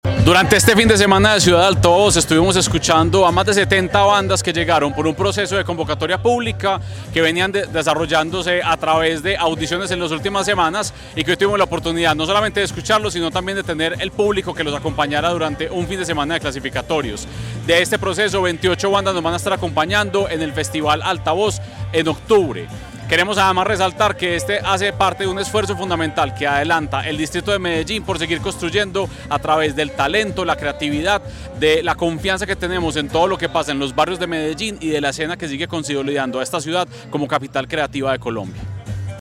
Declaraciones-del-secretario-de-Cultura-Ciudadana-Santiago-Silva-Jaramillo.-Altavoz.mp3